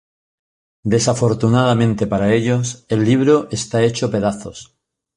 Pronúnciase como (IPA) /ˈlibɾo/